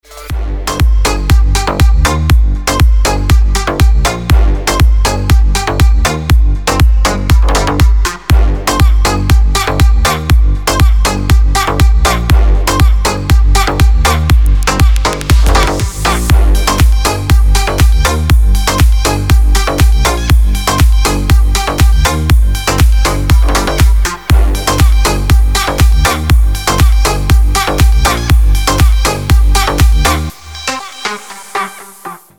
Клубная обрезка на рингтон скачать на телефон онлайн.